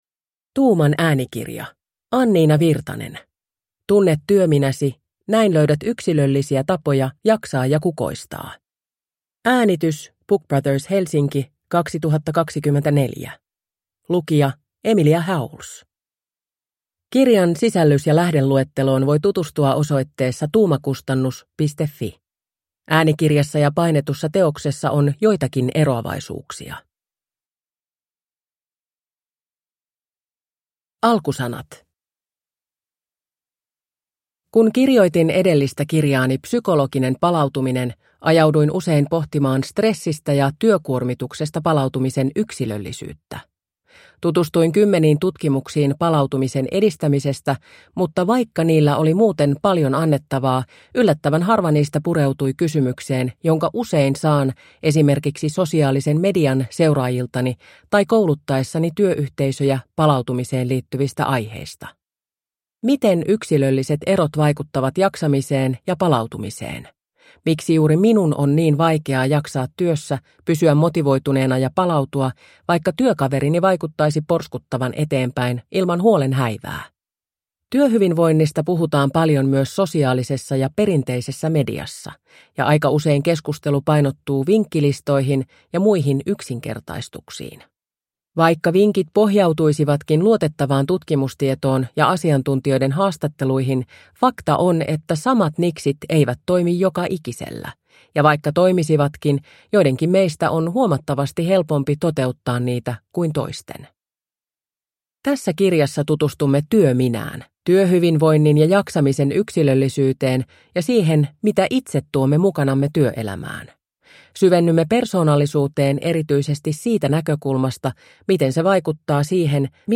Tunne työminäsi – Ljudbok